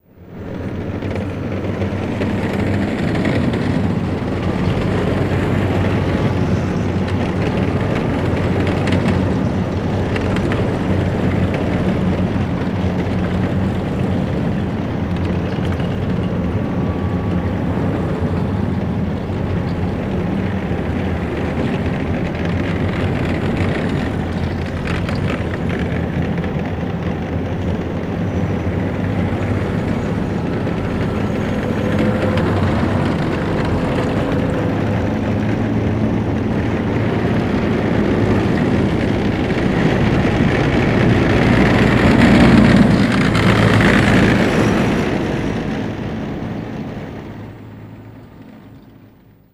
Armored Personnel Carrier Convoy